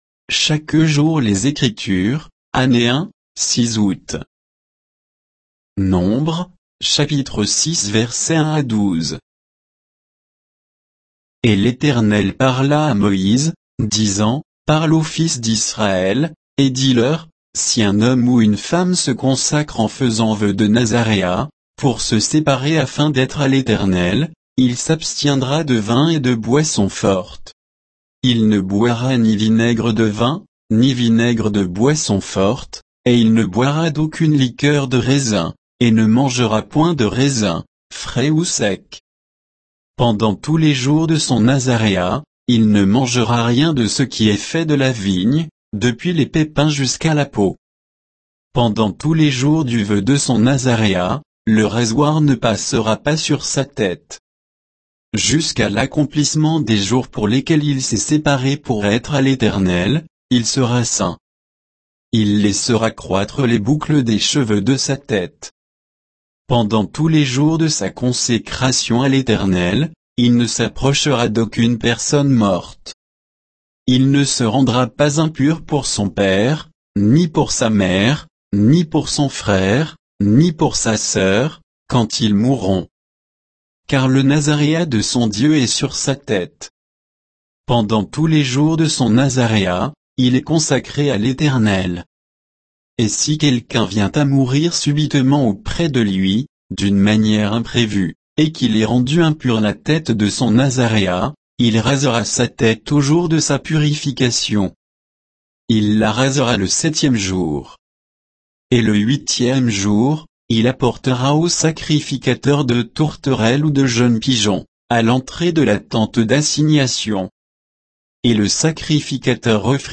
Méditation quoditienne de Chaque jour les Écritures sur Nombres 6